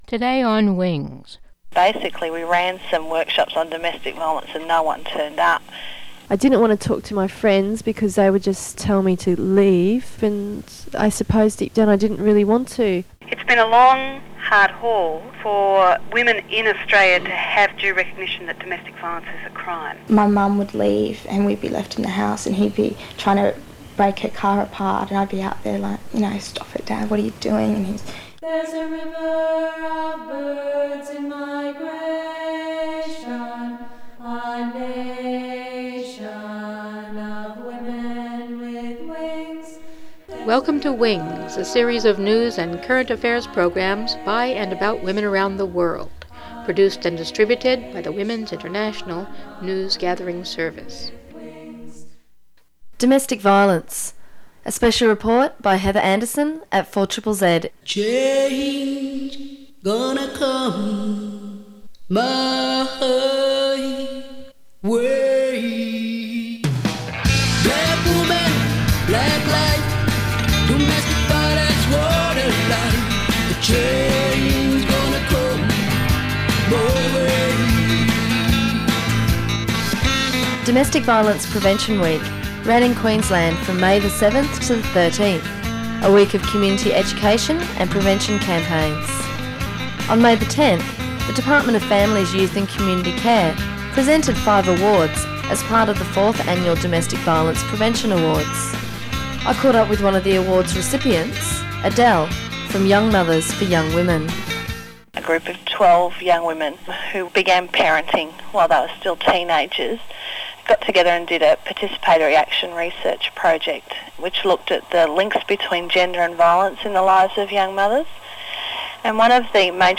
anonymous survivors of multi-generational domestic violence